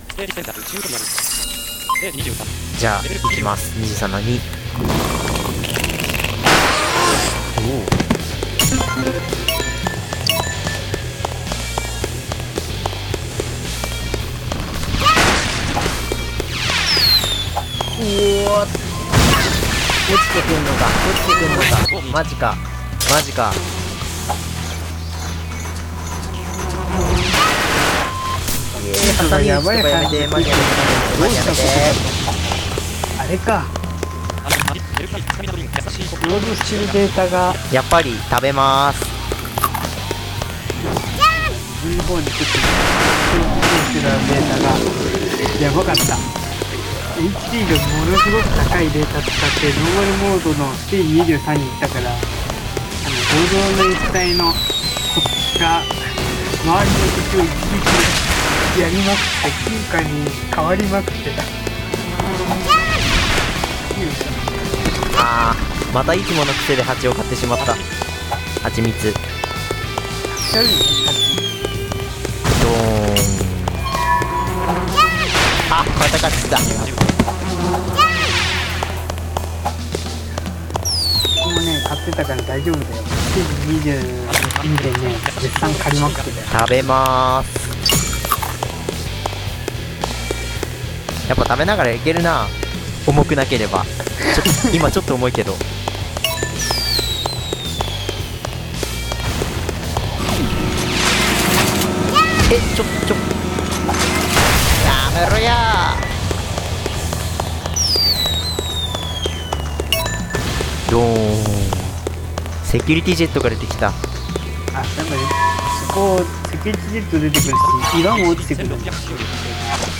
僕らの大冒険 III 実況プレイ
今回は、僕らの大冒険IIIの実況プレイです。開発スタッフ３人でセーブデータを回しながら、それぞれのプレイスタイルでクリアしていきます。
金貨稼ぎ、レベル上げ、つまらない往復など、暇そうなところはバシバシカットしました。
※３人別々のPC、および録音機材を用いていますので、一部音量などが均一化されていないパートがあります。